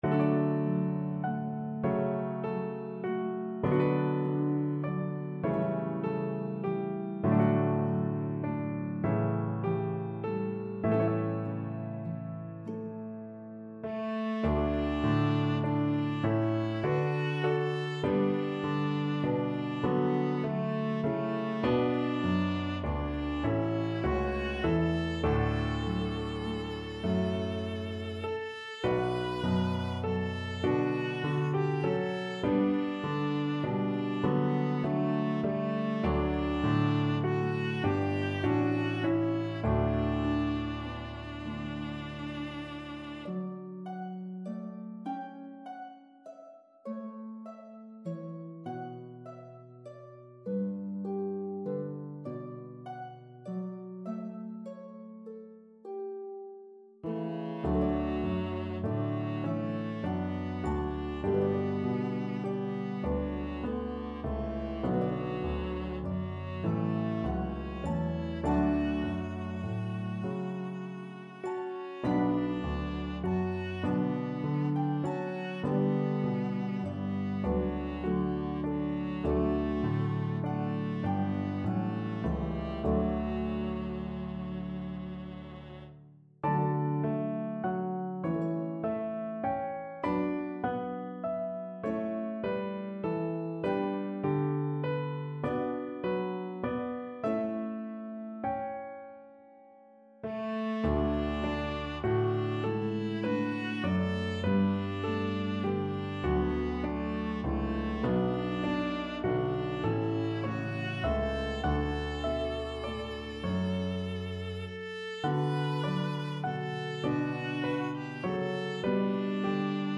Harp, Piano, and Viola version